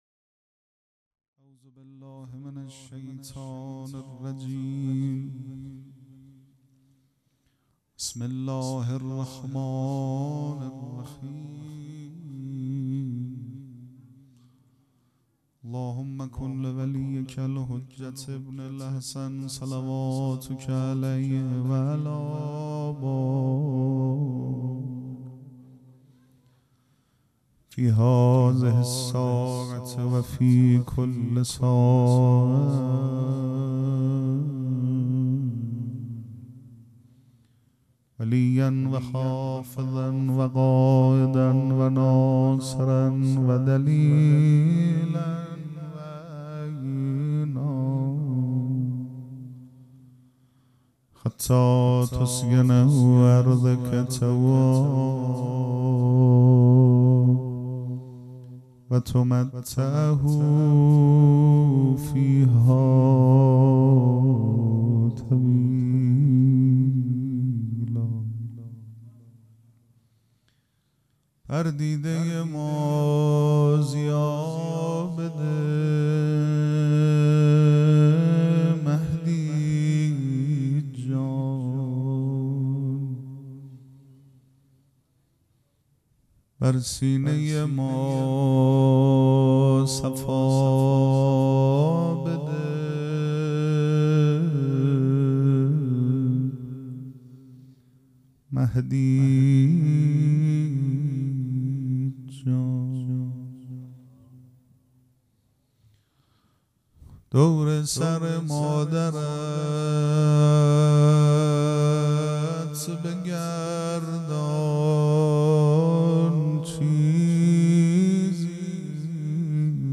پیش منبر
مراسم عزاداری شام شهادت حضرت زینب(س) پنجشنبه‌ ۲۸ بهمن سال ۱۴۰۰ حسینیه ریحانة‌الحسین (س)